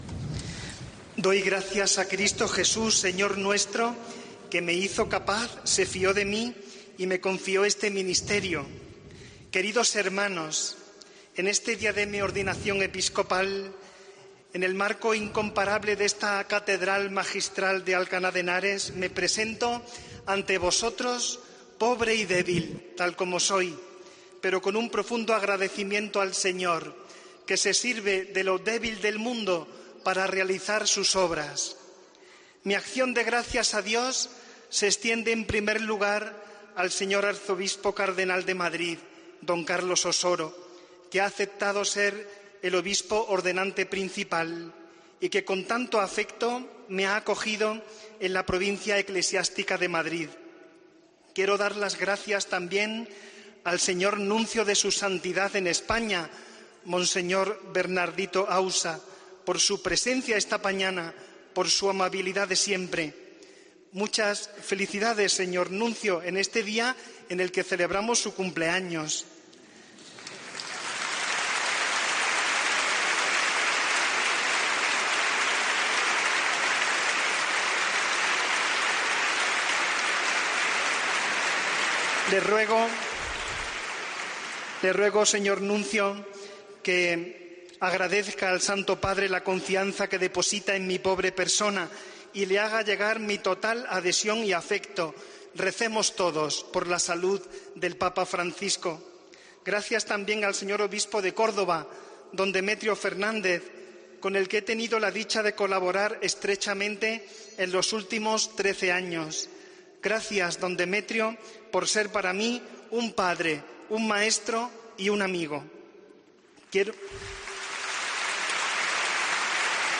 Escucha la alocución final del nuevo obispo de Alcalá de Henares, Antonio Prieto Lucena